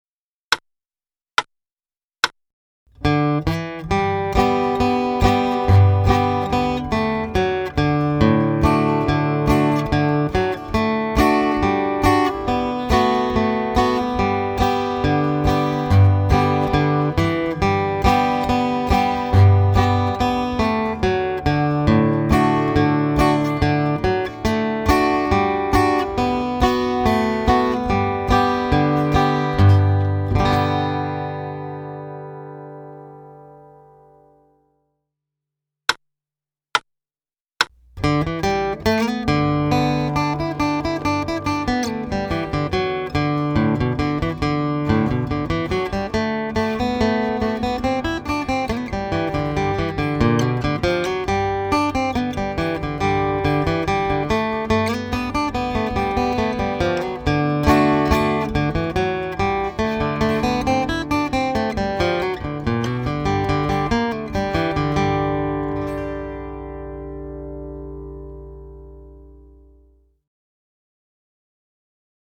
DIGITAL SHEET MUSIC - FLATPICK GUITAR SOLO
Bluegrass Classic, Guitar Solo
Multiple arrangements: simple Carter-Style and Fiddle-style